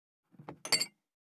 261,ガラスがこすれあう擦れ合う音,カトラリーの音,食器の音,会食の音,食事の音,カチャン,コトン,効果音,環境音,BGM,カタン,チン,コテン,
コップ効果音厨房/台所/レストラン/kitchen物を置く食器